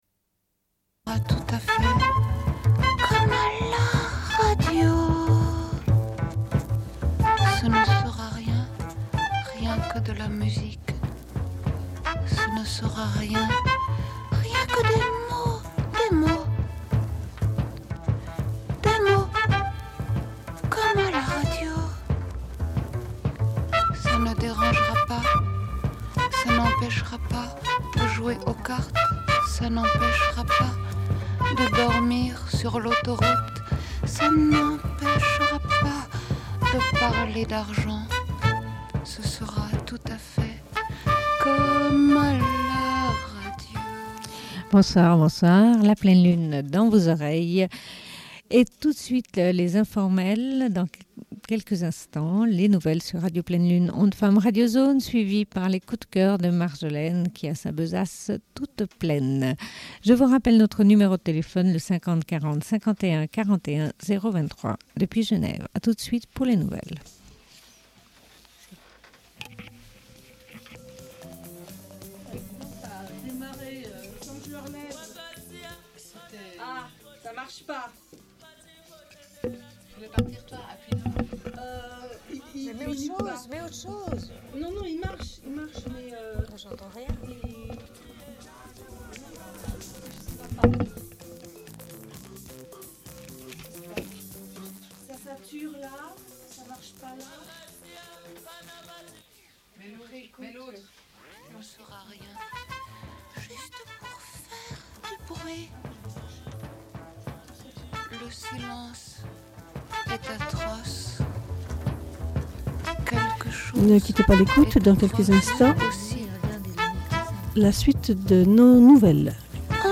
Bulletin d'information de Radio Pleine Lune du 01.02.1995 - Archives contestataires
Une cassette audio, face B31:14